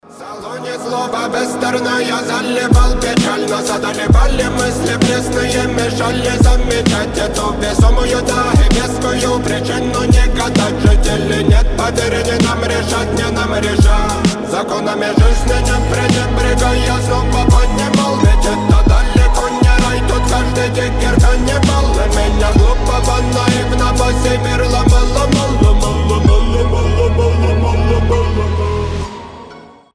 Хип-хоп
русский рэп